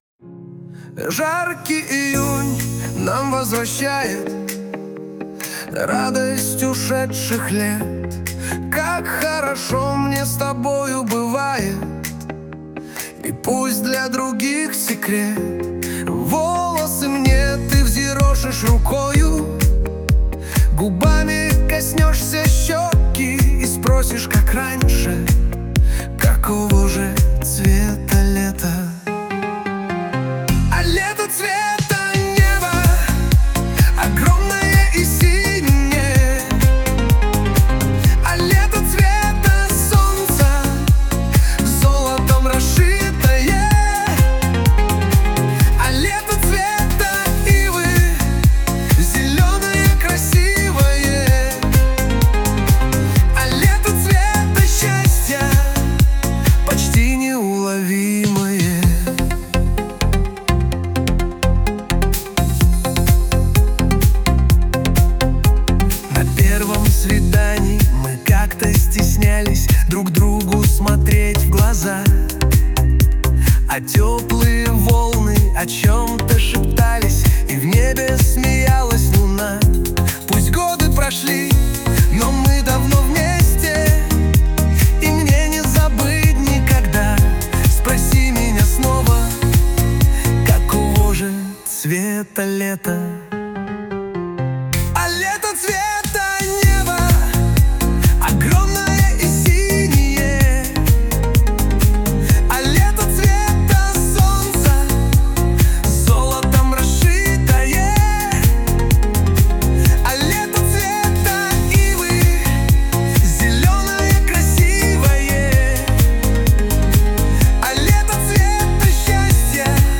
Попробуйте такой запрос: modern modal jazz, experimental harmony, free improvisation complex rhythms, dissonant chords, atonal, abstract Вот примеры генераций: